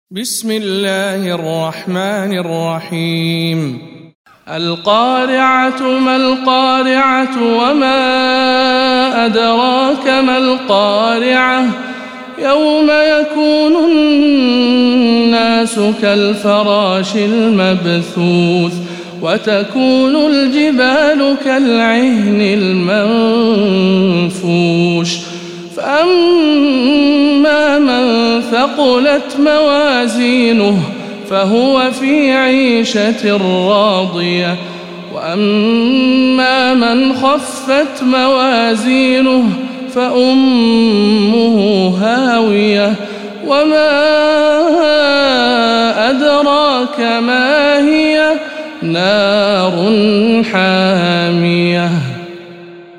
سورة القارعة- رواية هشام عن ابن عامر